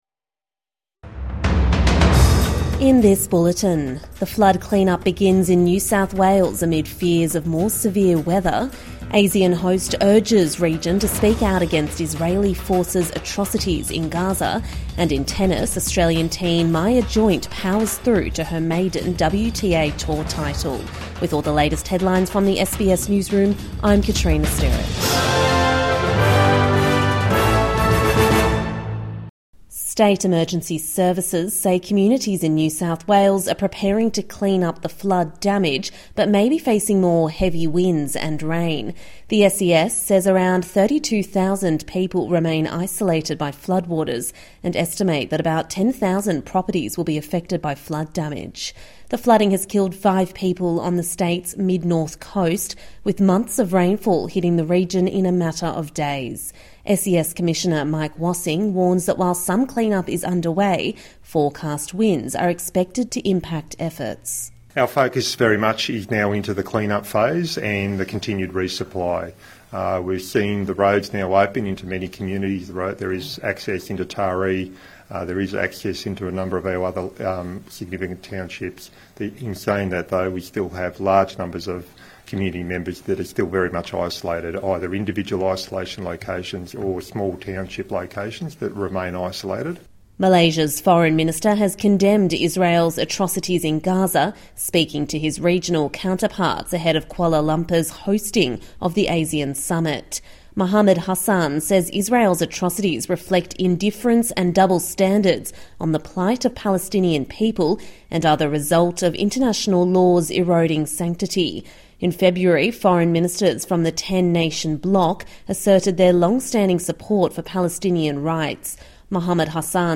Evening News Bulletin 25 May 2025